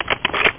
reload.mp3